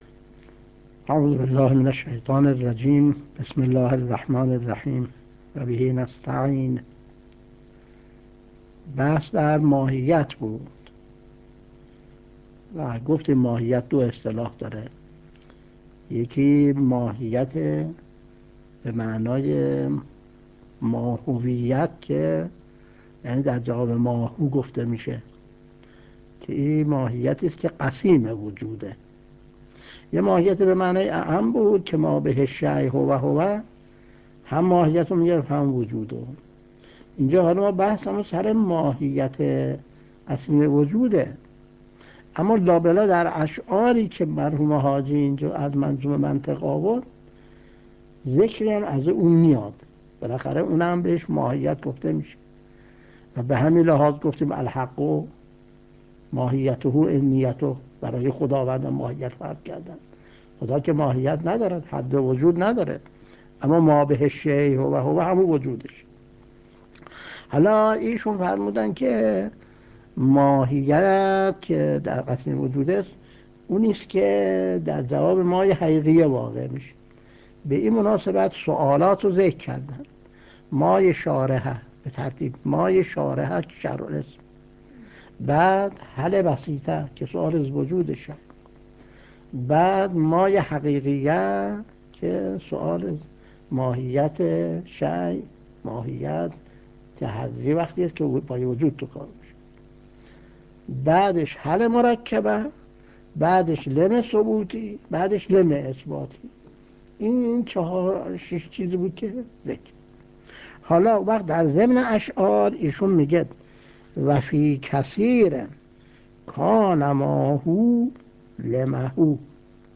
درس 75 : تعریف ماهیت و برخی از احکام آن (2) | آیت‌الله منتظری